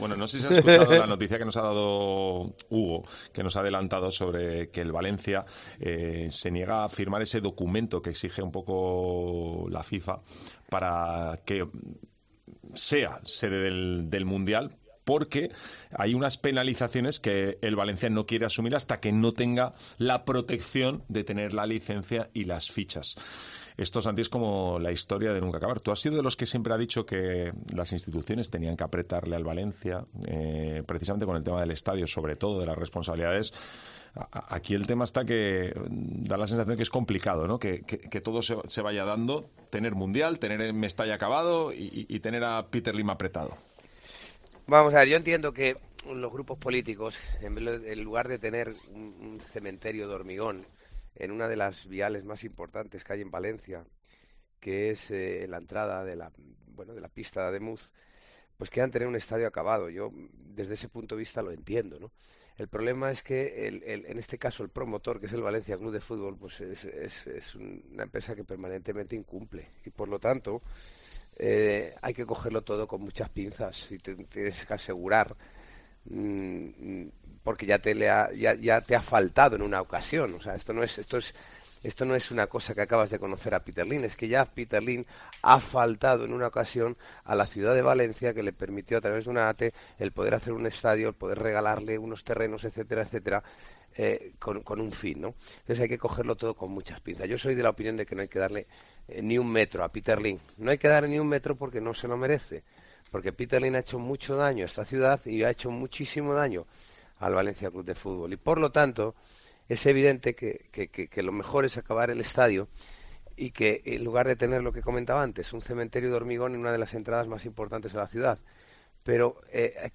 Santi Cañizares se ha pasado hoy por los micrófonos de Deportes COPE Valencia a raíz de la Noticia COPE sobre el Nou Mestalla y el Mundial 2030.